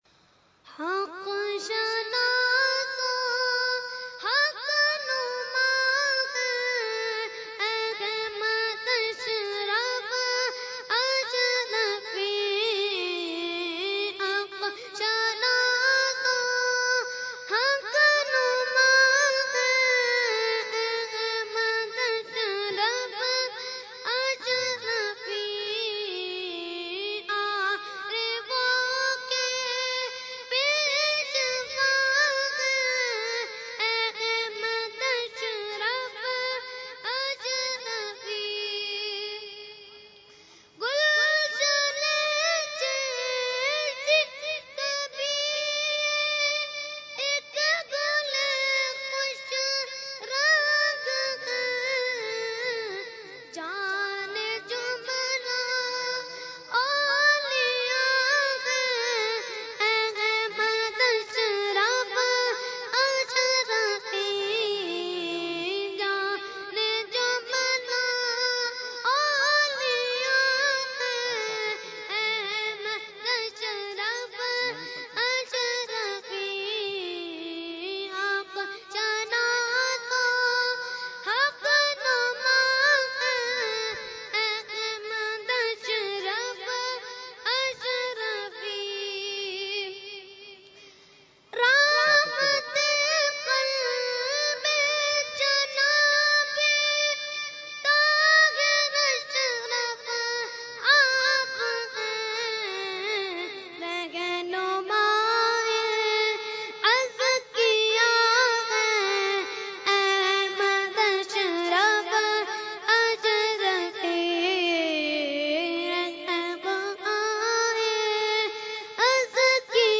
Category : Manqabat | Language : UrduEvent : Urs Ashraful Mashaikh 2016